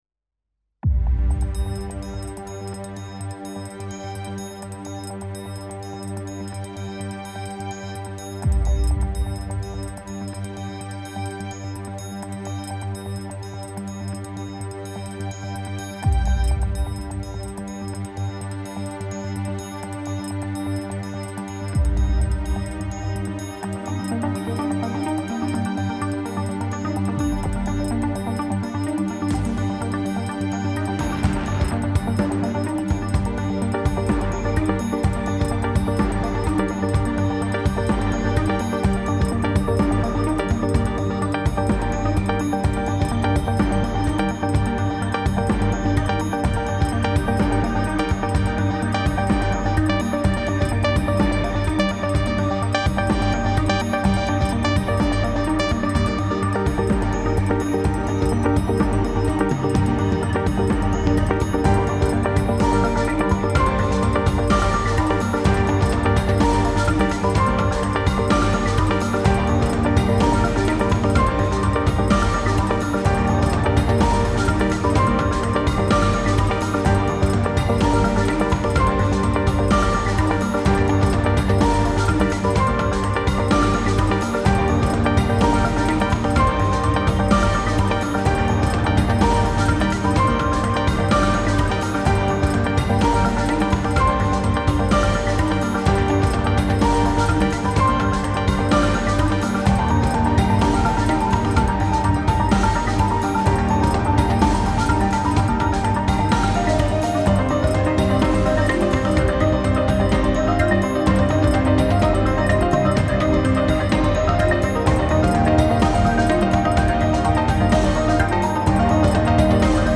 epique - percussions - profondeurs - paysage - ciel